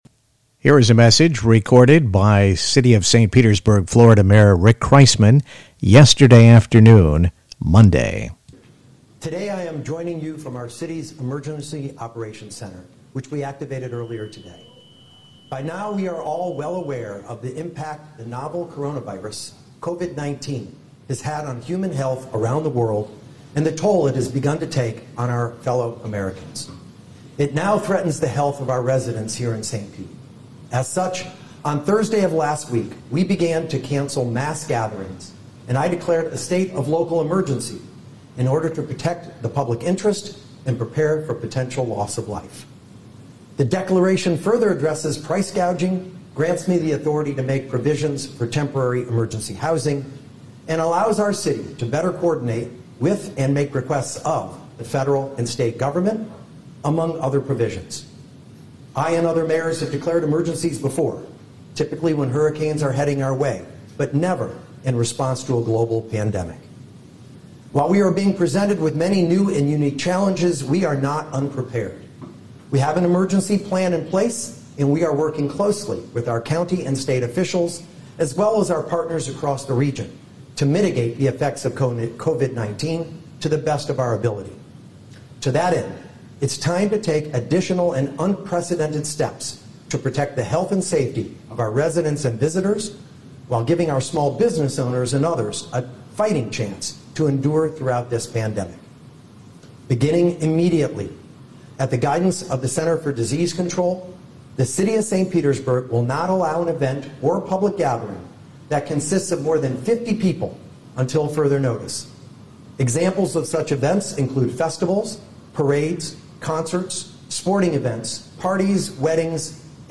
St. Pete Mayor Rick Kriseman Message 3-16-20